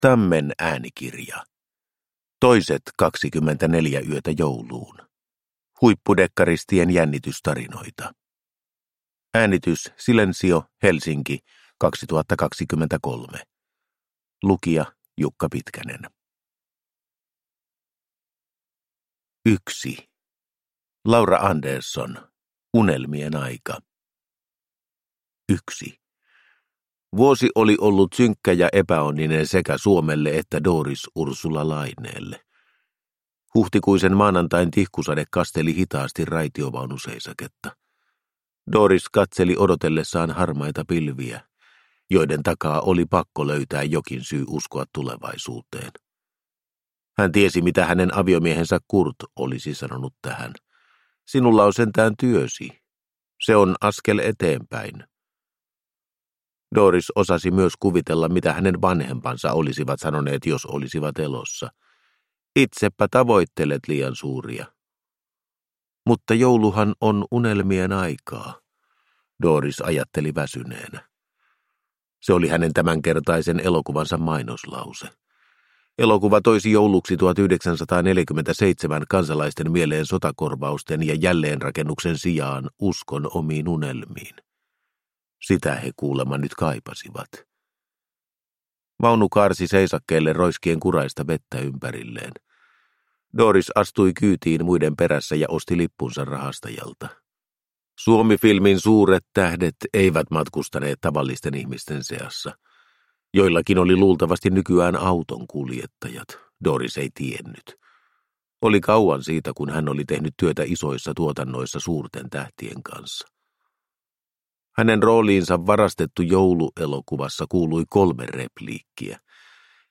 Toiset 24 yötä jouluun – Ljudbok